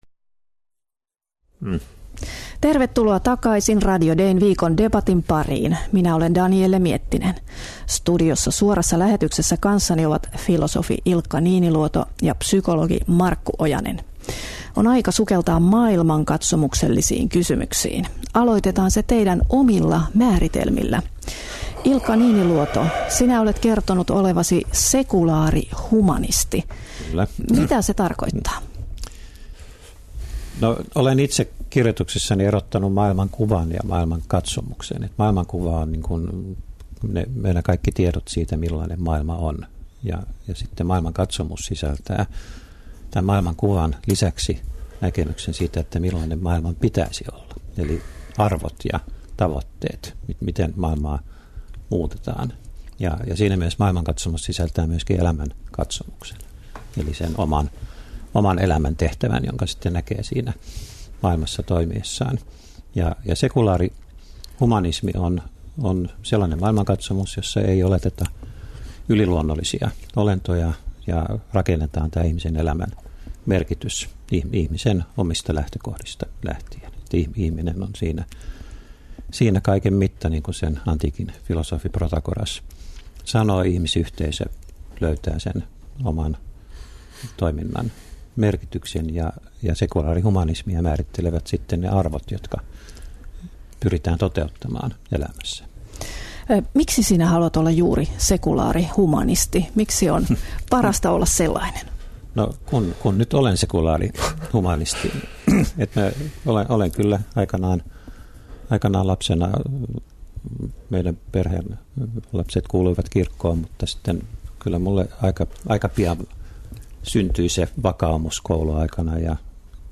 Radio Dein Viikon debatissa puhuttiin tällä viikolla siitä, mikä merkitys sekulaarilla tai kristillisellä maailmankatsomuksella on onnellisuuden takeeksi.